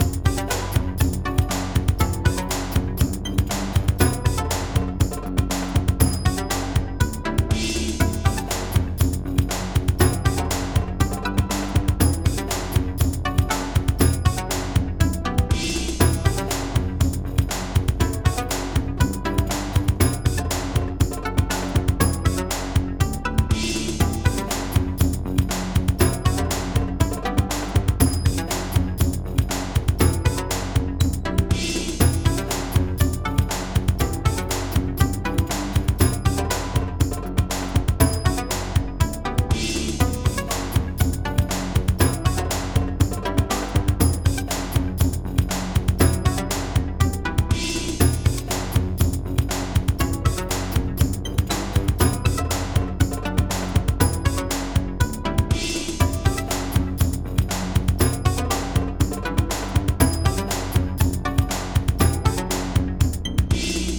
Desert VGM loopable, CC Zero as always..